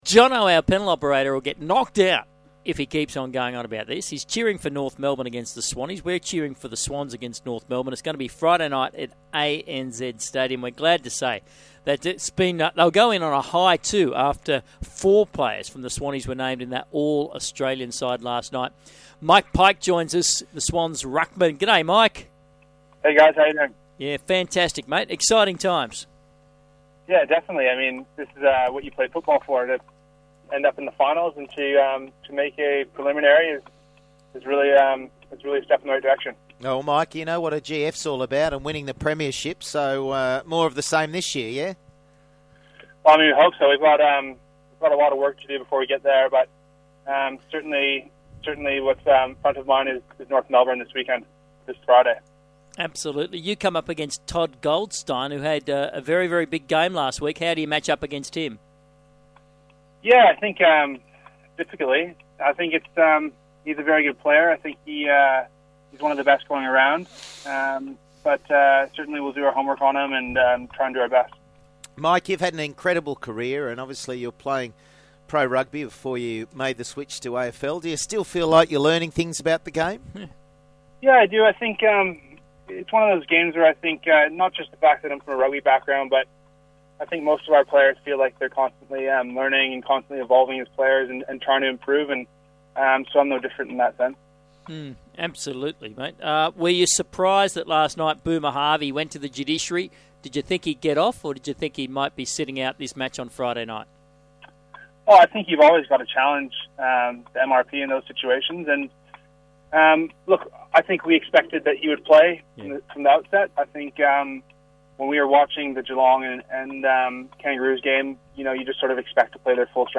Sydney Swans ruckman Mike Pyke appeared on Sky Sports Radio's Big Sports Breakfast program on Wednesday September 17, 2014